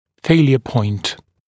[‘feɪljə pɔɪnt][‘фэйлйэ пойнт]точка разрыва (о материале)